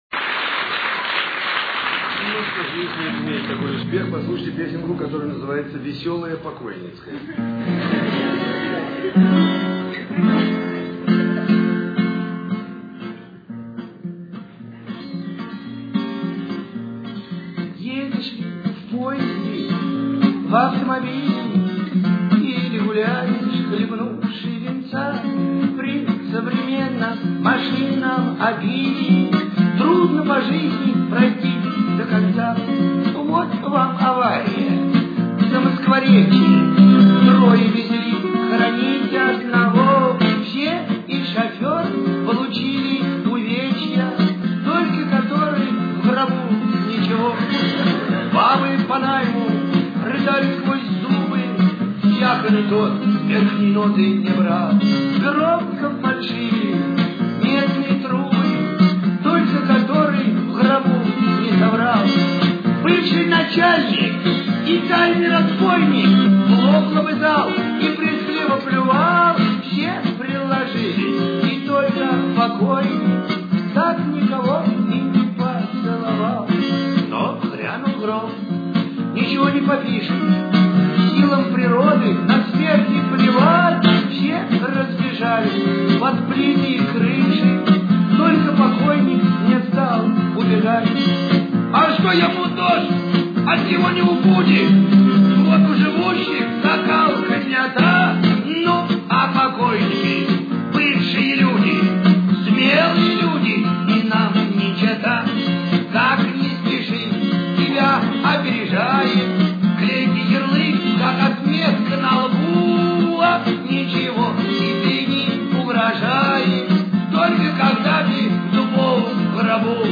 (с концерта, быстрый темп)